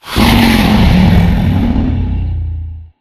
growl4.ogg